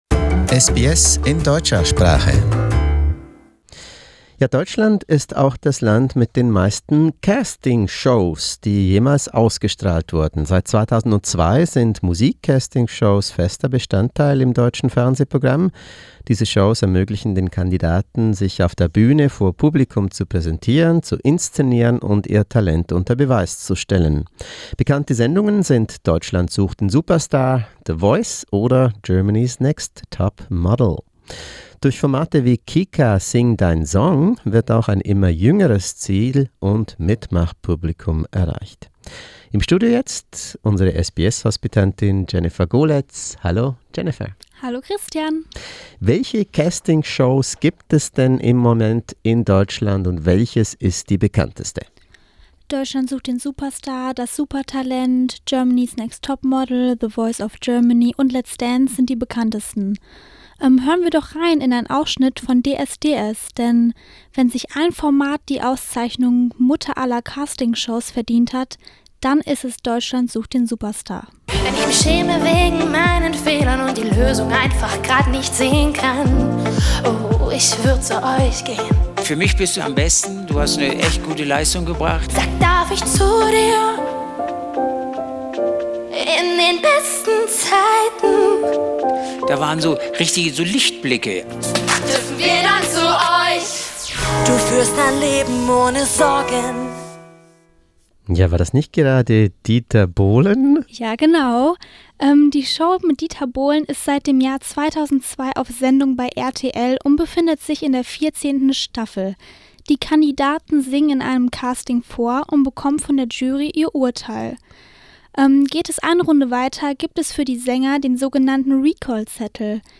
How is the situation in Germany? Listen to a SBS studio talk to find out more!